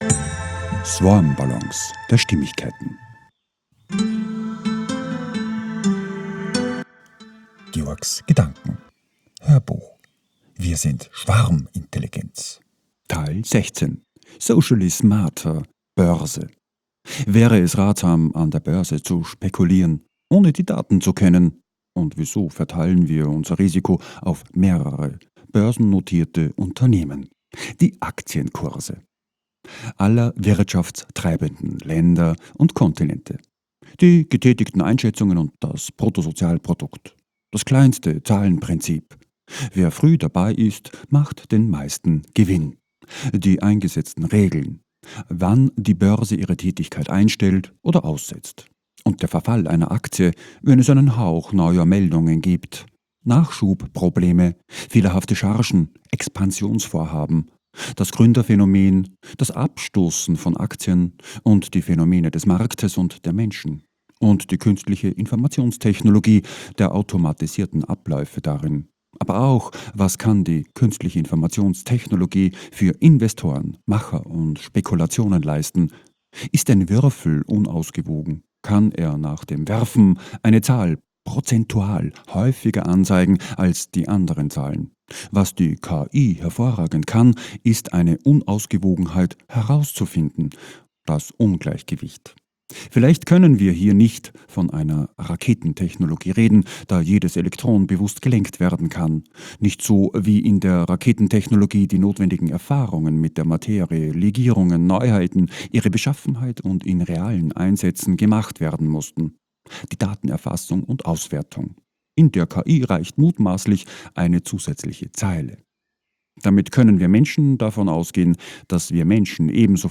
HÖRBUCH - 016 - WIR SIND SCHWARMINTELLIGENZ - Socially SMARTER - BÖRSE